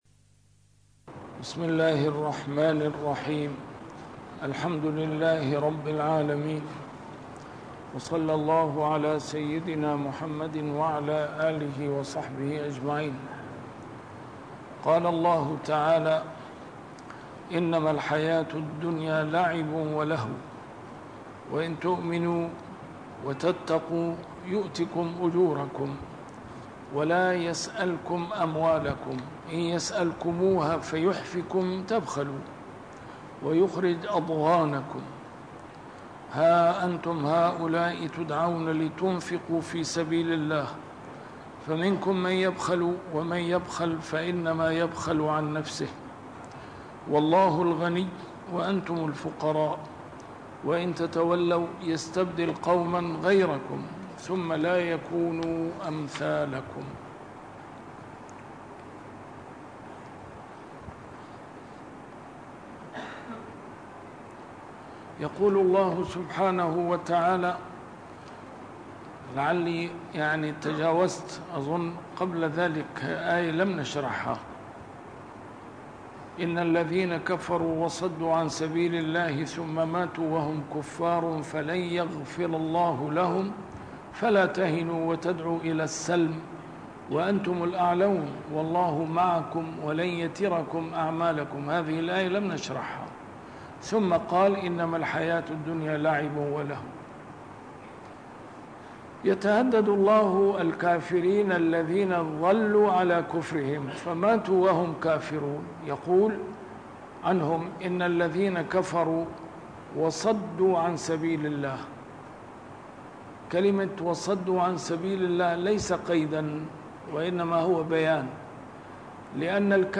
نسيم الشام › A MARTYR SCHOLAR: IMAM MUHAMMAD SAEED RAMADAN AL-BOUTI - الدروس العلمية - تفسير القرآن الكريم - تسجيل قديم - الدرس 643: محمد 34-36
تفسير القرآن الكريم - تسجيل قديم - A MARTYR SCHOLAR: IMAM MUHAMMAD SAEED RAMADAN AL-BOUTI - الدروس العلمية - علوم القرآن الكريم - الدرس 643: محمد 34-36